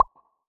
Bubble Pop Shoot v3.wav